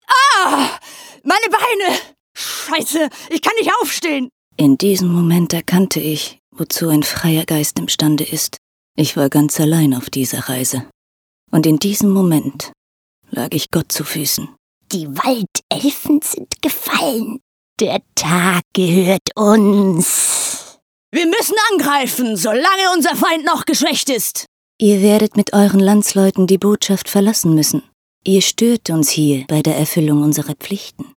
Fundiert ausgebildete Sprecherin, Schauspielschule, Native Speaker auch für Schweizer Dialekte, Sprecherin, mittel bis tiefe Stimme, Hamburg, Schweiz, TV- und Radio Spots, Voice Over, Imagefilme, Industriefilme, E-Learnings, Synchron, Computerspiele
Sprechprobe: Sonstiges (Muttersprache):